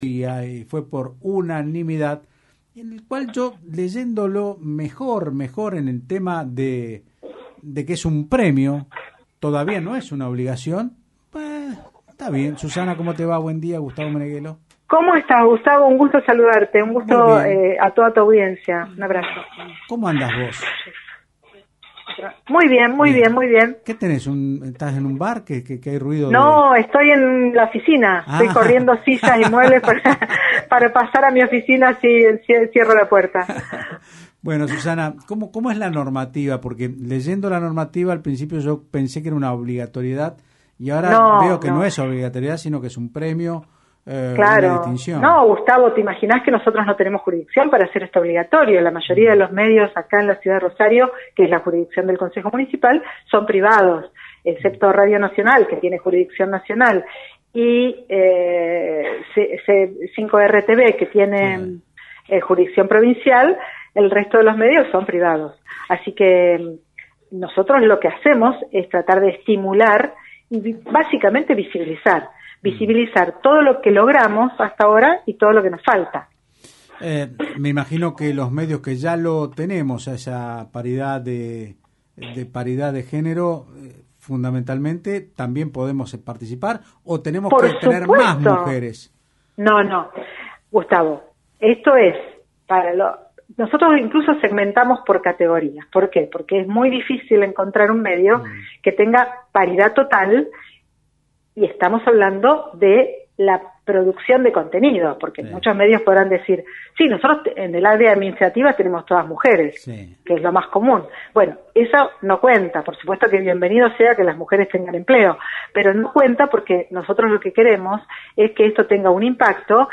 La autora de la iniciativa Susana Rueda explicó el proyecto en Otros Ámbitos (Del Plata Rosario 93.5).